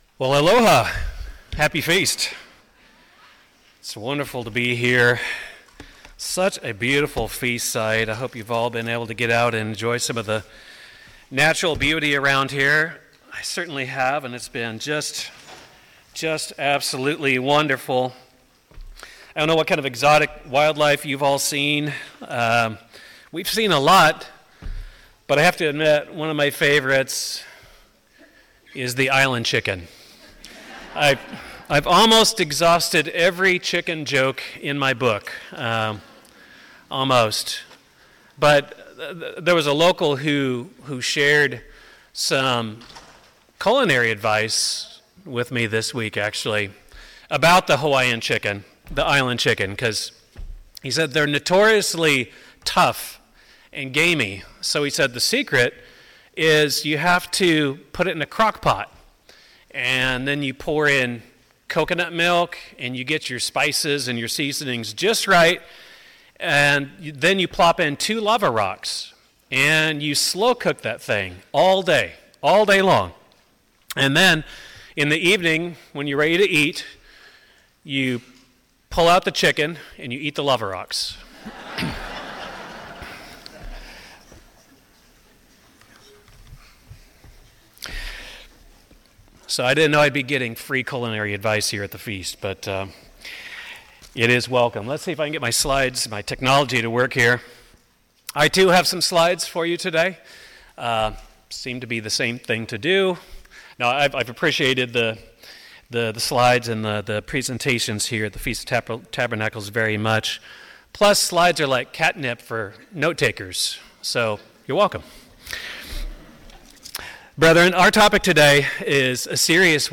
This sermon explores the pervasive influence of fear in modern society, highlighting how it saturates society, heightens anxieties, and robs people of hope. The message emphasizes that fear is not just a cultural phenomenon but a spiritual issue, deeply embedded in the human condition.